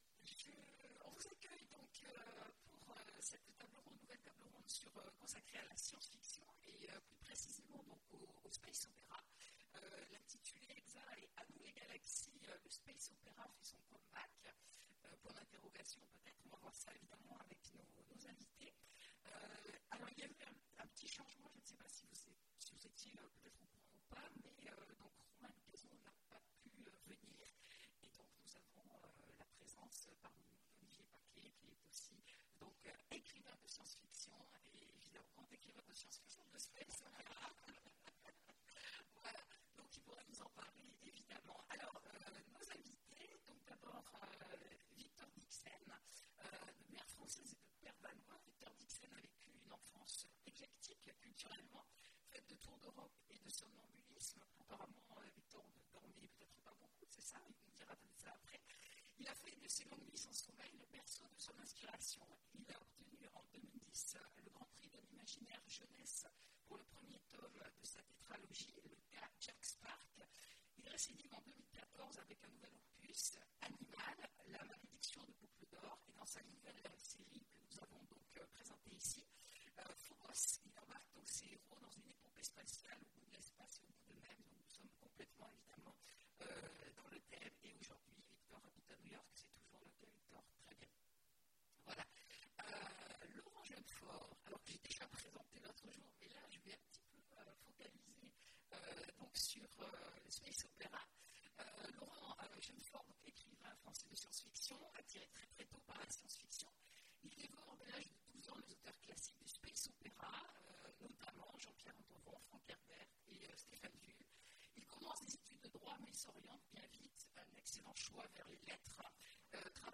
Imaginales 2017 : Conférence À nous les galaxies ! Le space-opera fait son come back !